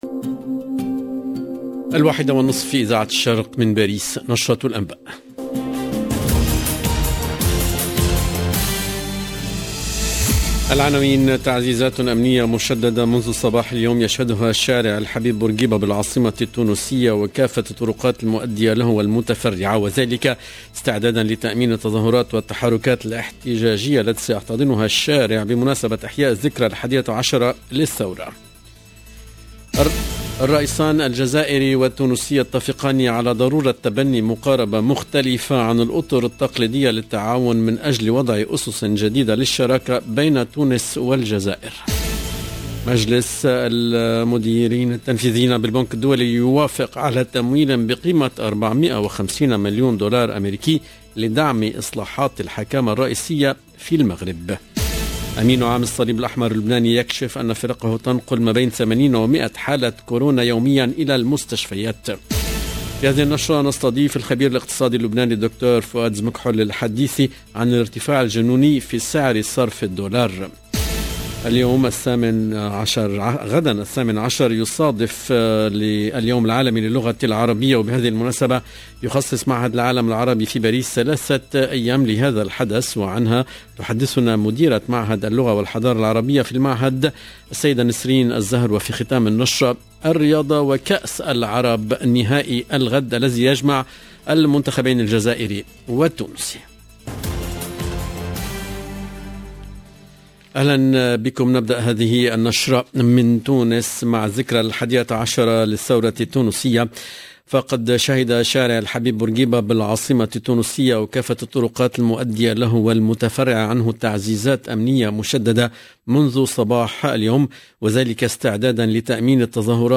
LE JOURNAL EN LANGUE ARABE DE LA MI-JOURNEE DU 17/12/21